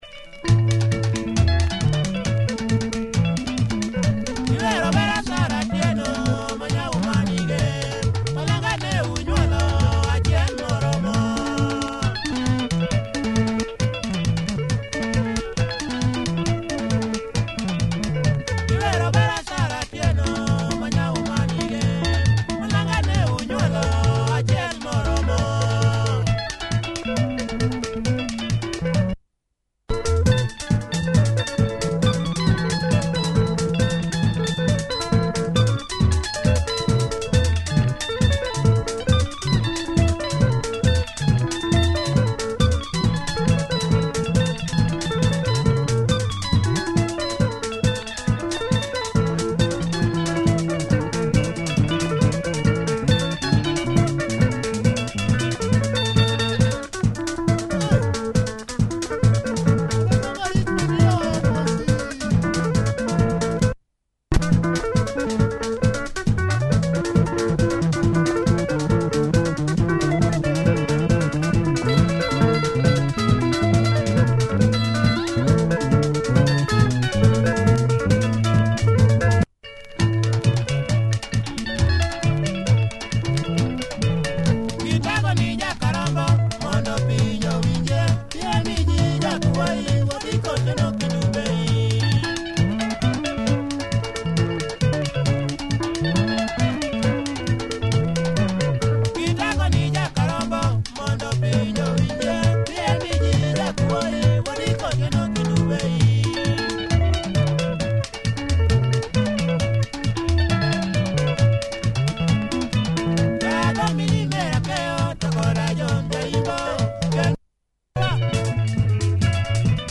Nice rhythmical luo benga, check audio of both sides! https